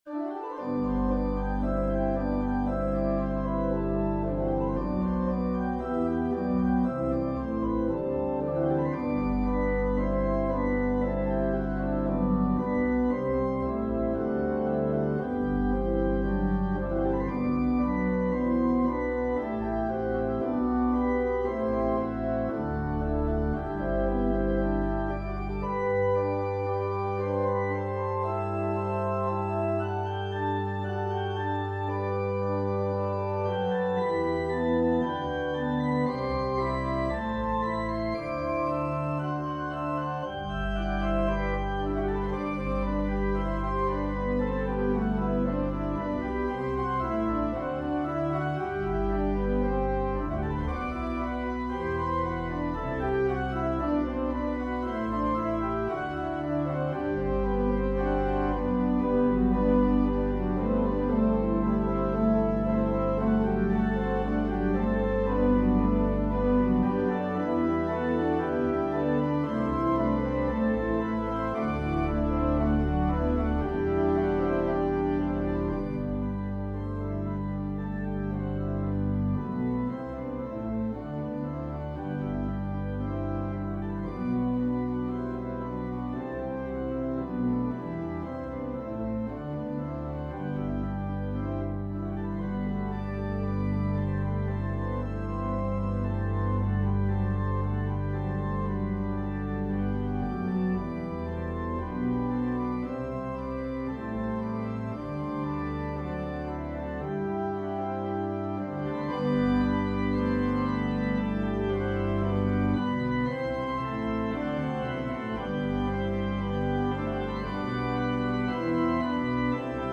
An organ solo arrangement.
Voicing/Instrumentation: Organ/Organ Accompaniment